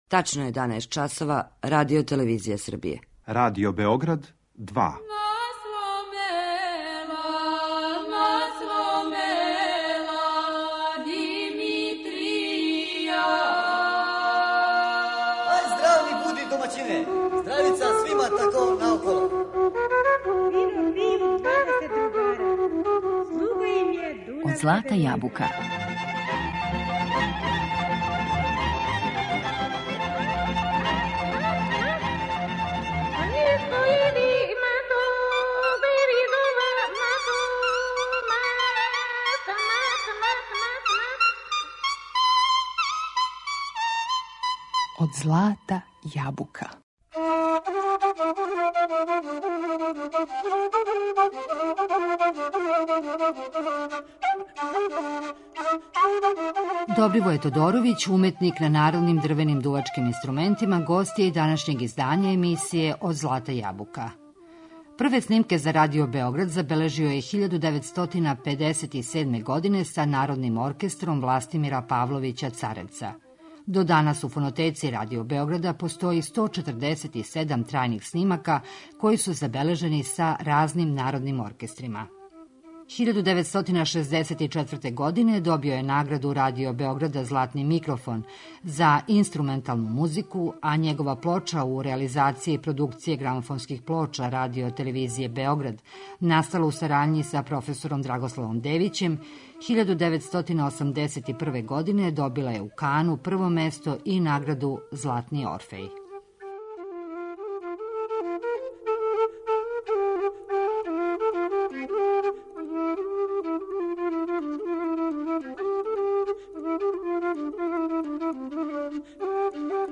гост је уметник на традиционалним дрвеним дувачким инструментима